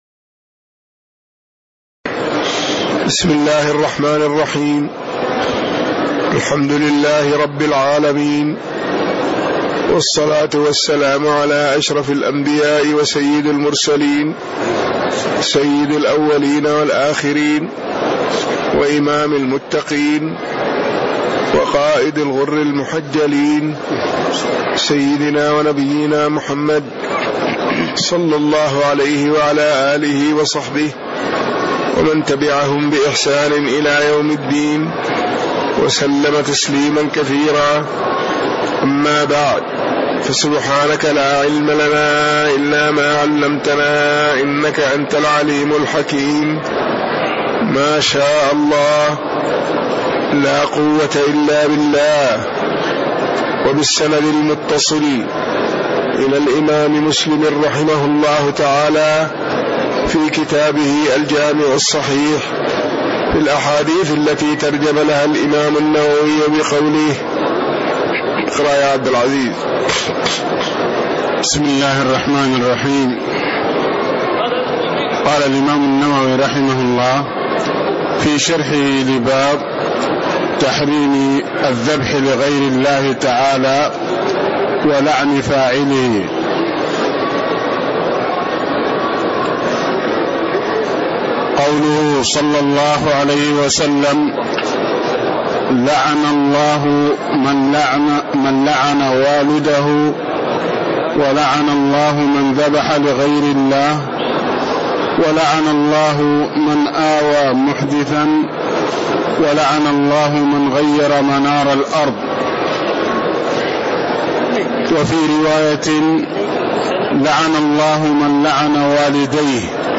تاريخ النشر ١١ جمادى الآخرة ١٤٣٦ هـ المكان: المسجد النبوي الشيخ